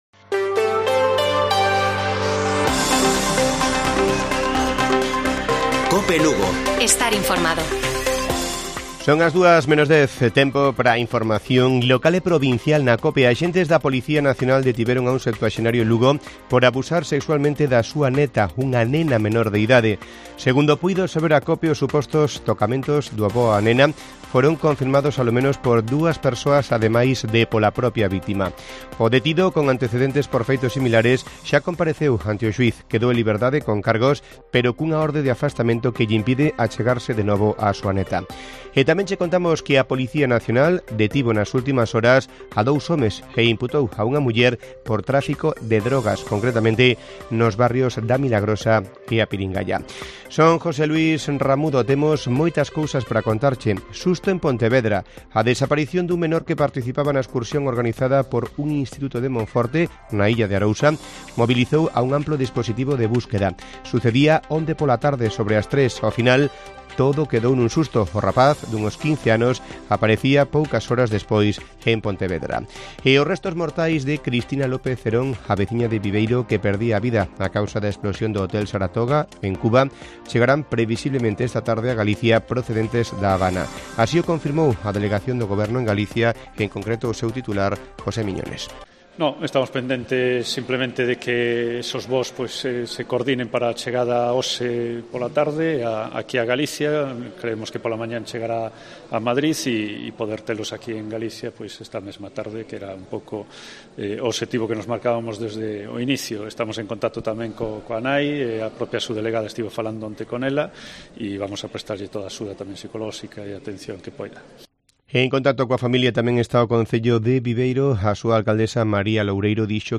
Informativo Mediodía de Cope Lugo. 12 de mayo. 13:50 horas